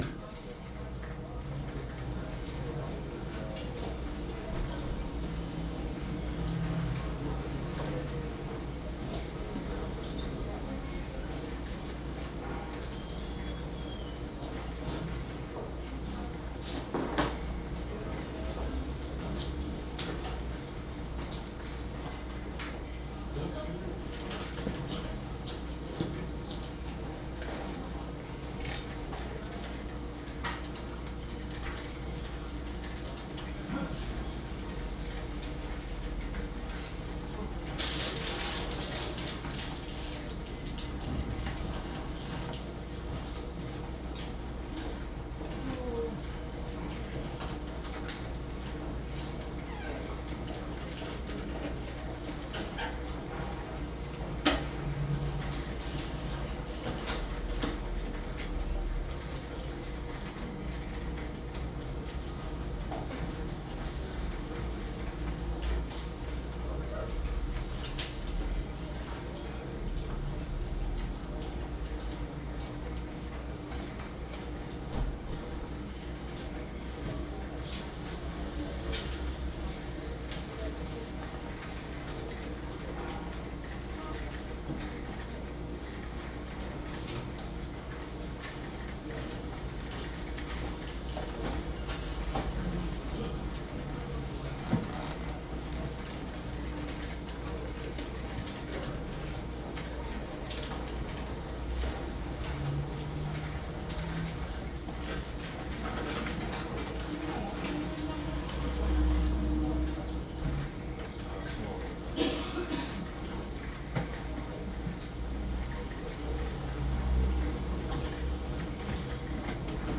Medium Office_2020_10_22_Open Space Reaper Office.wav